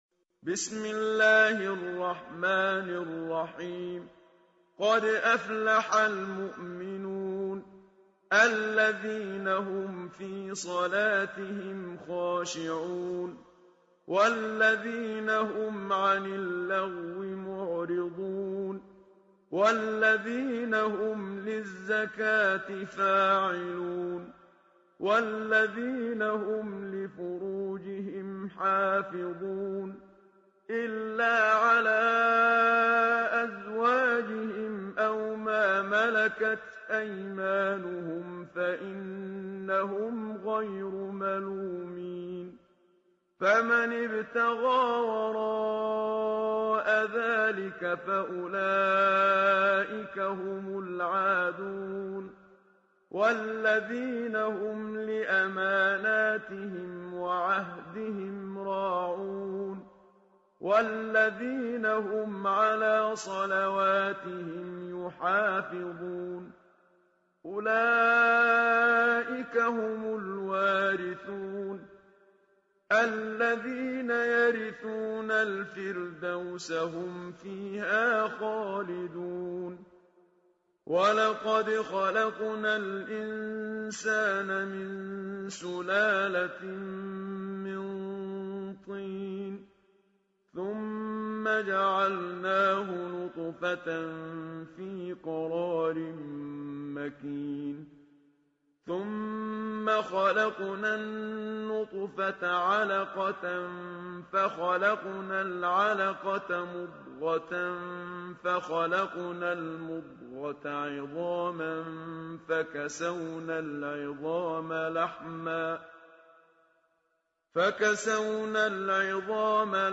ترتیل جزء «۱۸» قرآن کریم هدیه به شهدای شهرستان ملایر
به گزارش نوید شاهد همدان ، ترتیل جزء ۱۸ قرآن کریم با صدای استاد منشاوی به پیشگاه مقدس به ۱۱۰۰ شهید شهرستان ملایر تقدیم می‌شود.